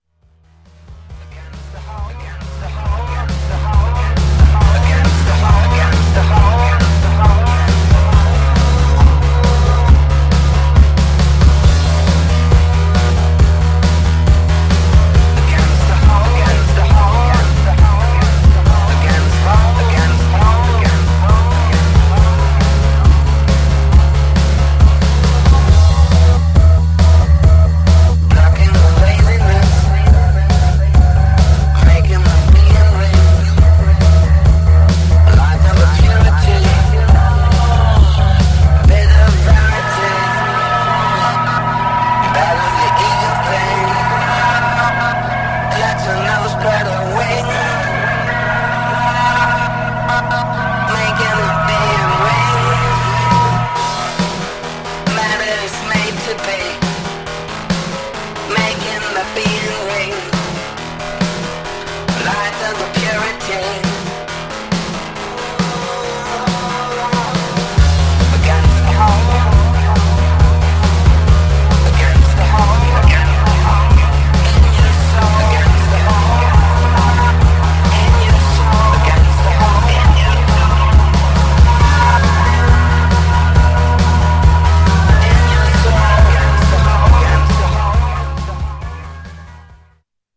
Styl: House, Breaks/Breakbeat